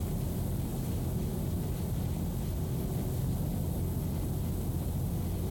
industrialtest_jetpack_loop.ogg